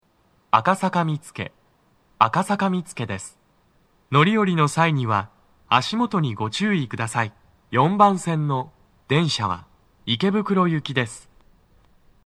足元注意喚起放送が付帯されています。
男声
到着放送1